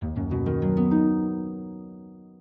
soundblocks_harp.ogg